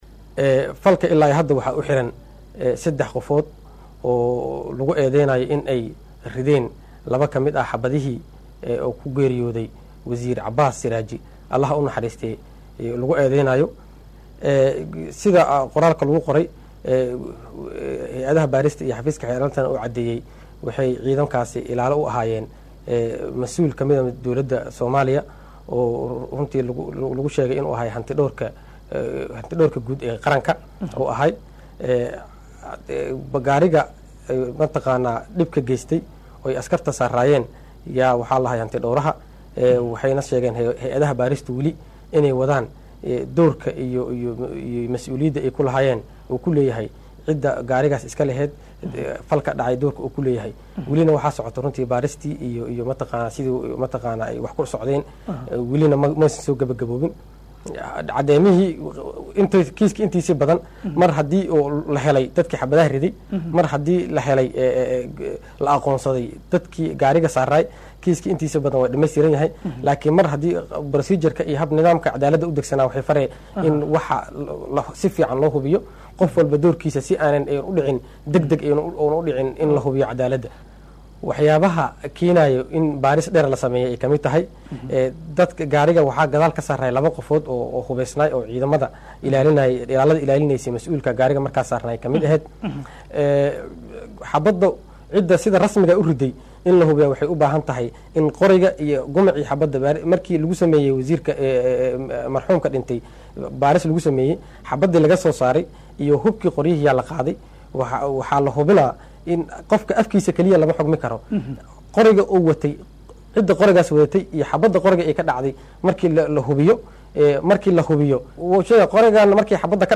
Gudoomiyaha Maxkamada darajada Koowaad ee Ciidamada Qalabka sida Gaashaanle sare Xasan Cali Nuur Shuute oo ka hadlay dilkii Wasiir Cabaas Siraaji.